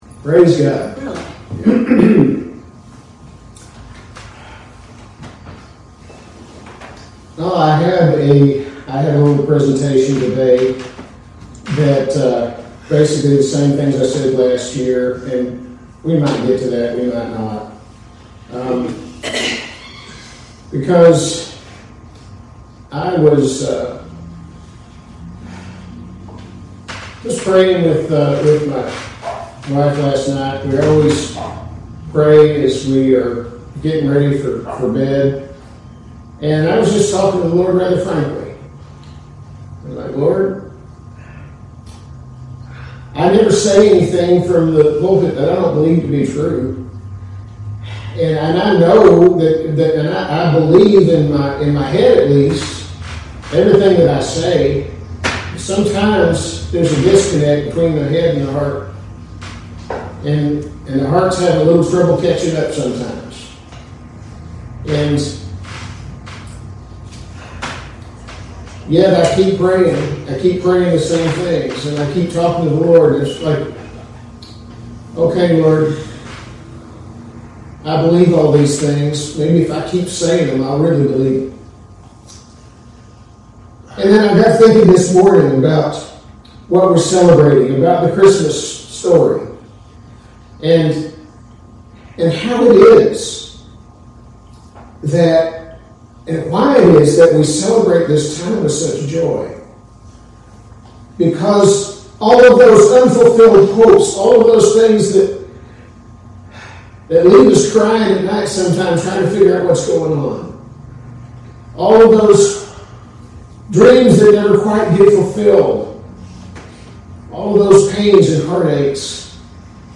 As part of our traditional Christmas celebration, I read the story from Luke 2:1-14 and add a little commentary.